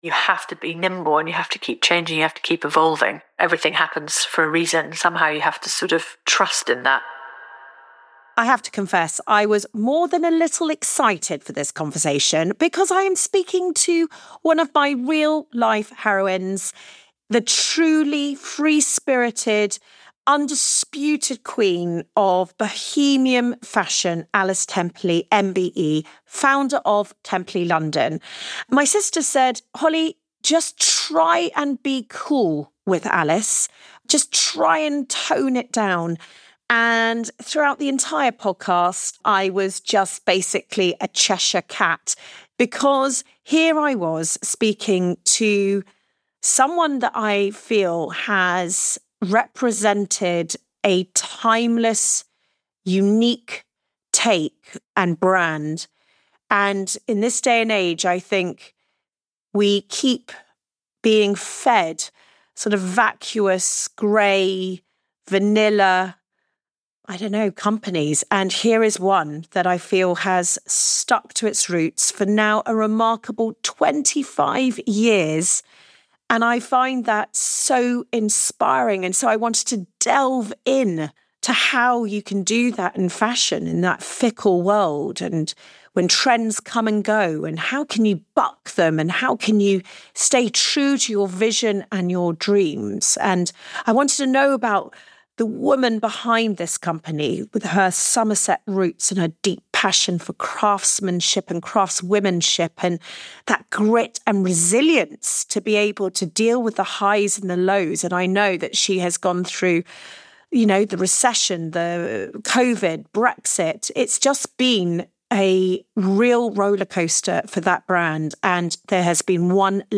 In this conversation, she opens up about the challenges she’s faced in the ever-evolving fashion industry, the resilience that carried her through and the perseverance that helped cement Temperley London as being a quintessentially British brand. She also reflects on the importance of finding her flock and reveals why the key to success is often slowing down not speeding up.